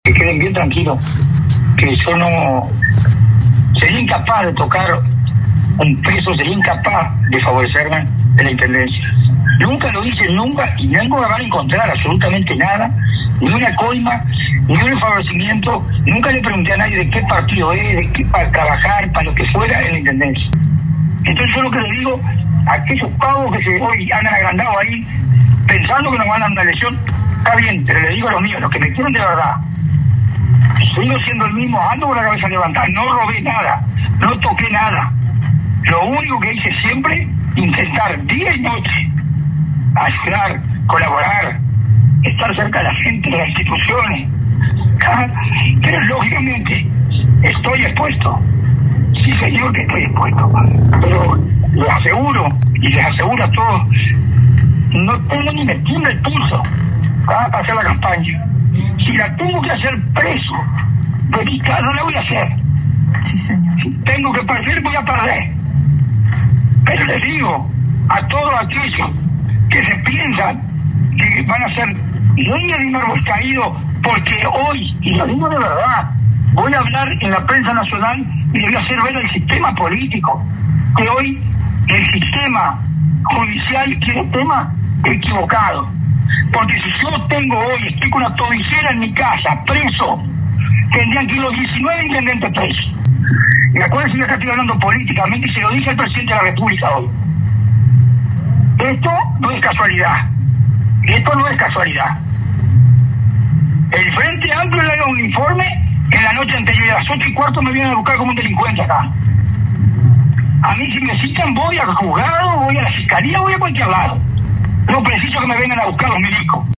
“Si yo tengo hoy, estoy con una tobillera en mi casa preso, tendrían que ir los 19 intendentes presos, los 19 secretarios generales presos, porque si no podés hacer la gestión de un vecino y por eso te van a condenar, entonces tienen que ir todos presos, que vayan haciendo fila”, dijo el ex intendente a Difusora Soriano.